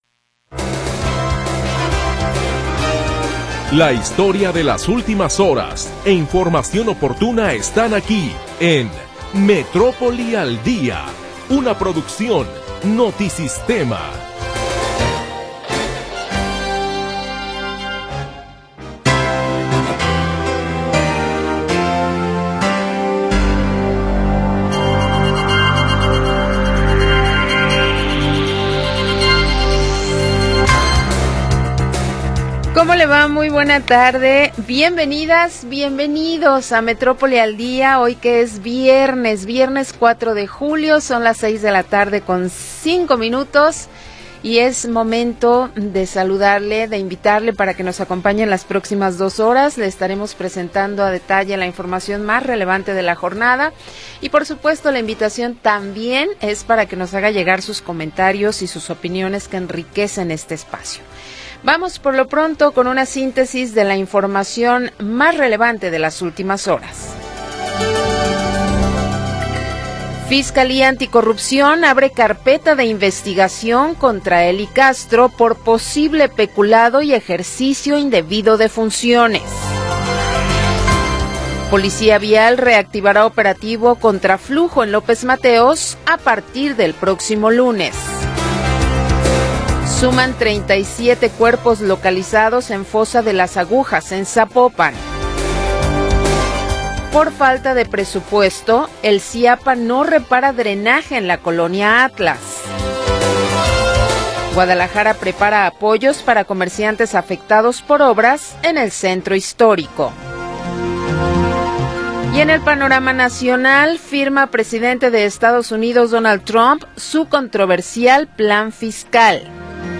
Primera hora del programa transmitido el 4 de Julio de 2025.